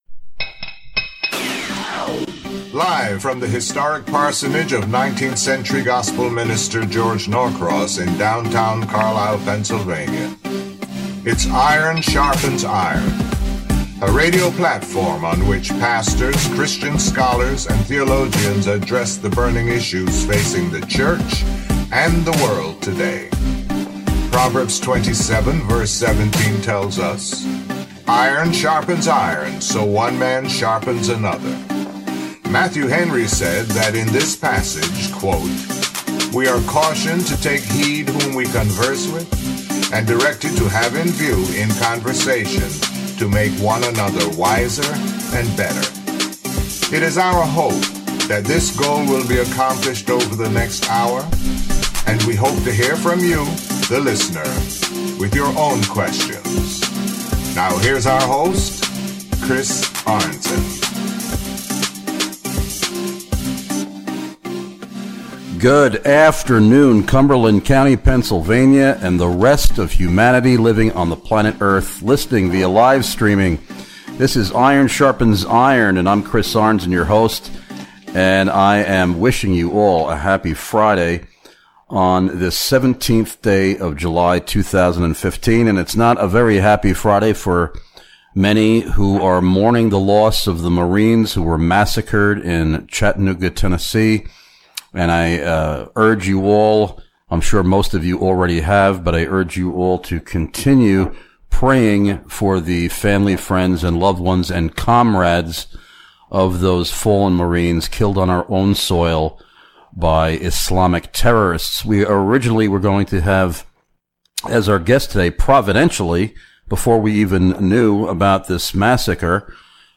ISI Radio Show